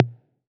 BCONGA HI.wav